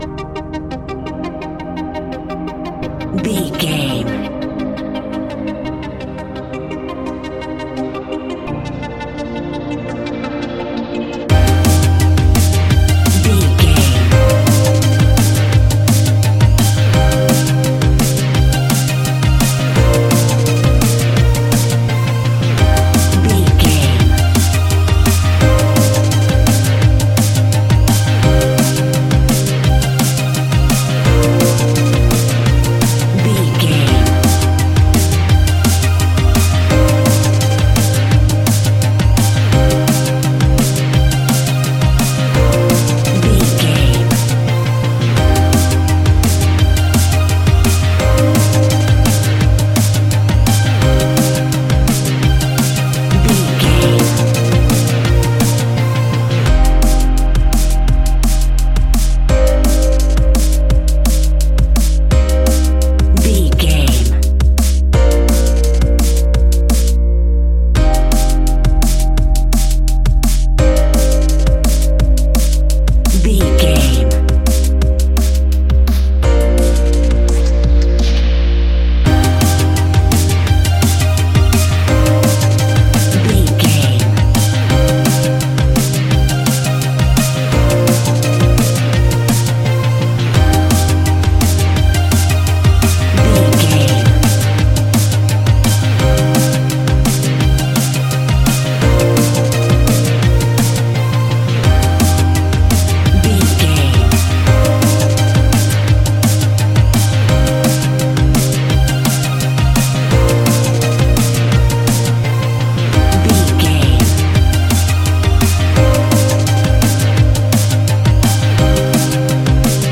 Ionian/Major
electronic
techno
trance
synths
synthwave
glitch